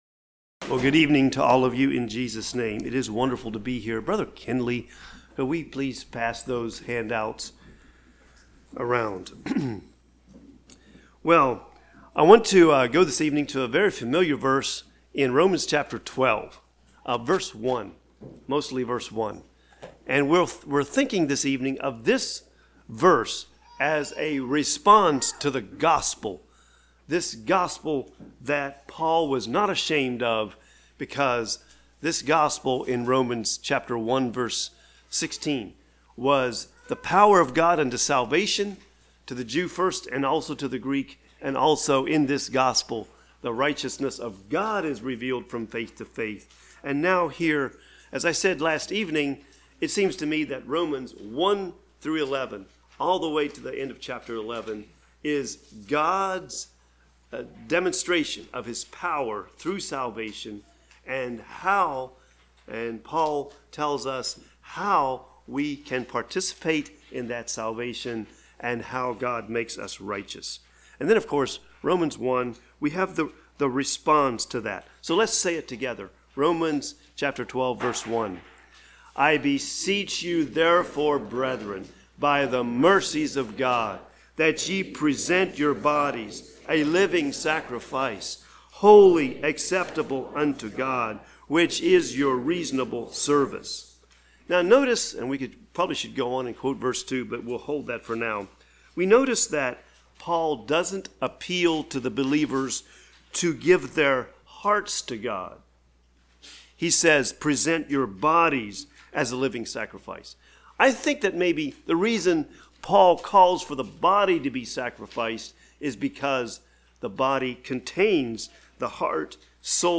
1 Peter 2:9 Service Type: Spring Conference Meetings Topics: Bodily Sacrifice , Priest « Gripped by the Gospel